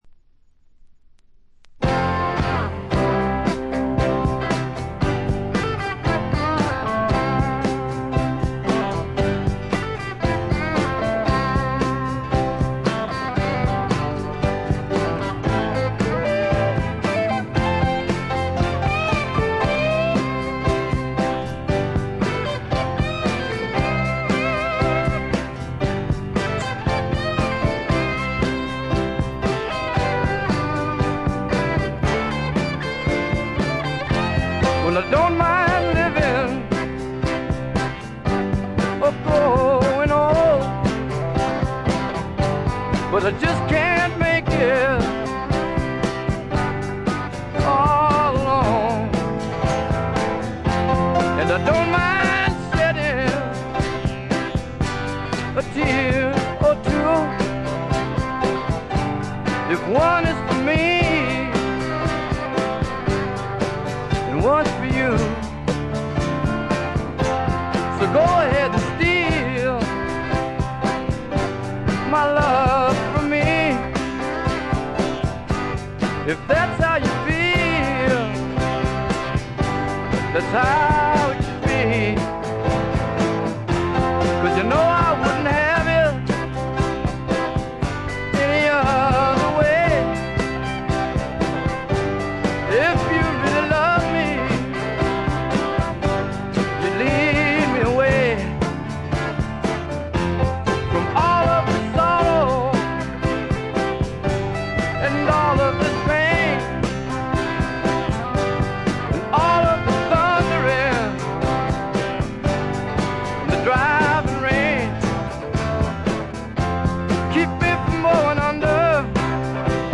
中身はヴォーカルも演奏も生々しくラフなサウンドがみっちり詰まっている充実作で、名盤と呼んでよいでしょう。
試聴曲は現品からの取り込み音源です。